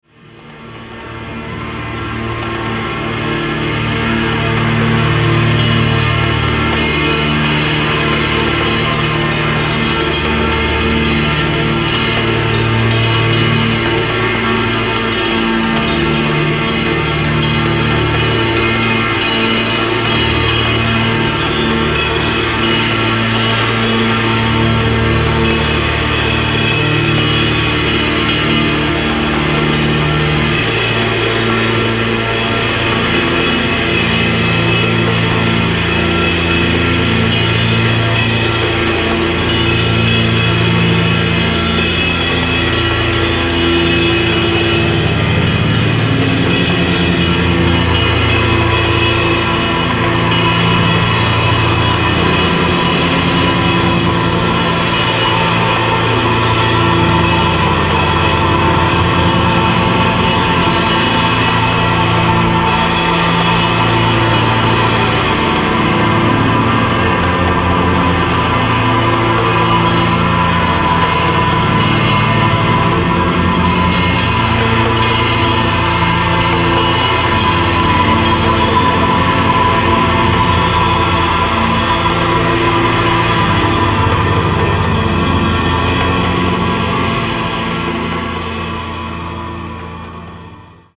試聴は、ボンゴを交えたオーガニックグルーヴが細胞の隅々にまで染み渡る一幕から。
キーワード：サイケ　ミニマル　霊性　　空想民俗　即興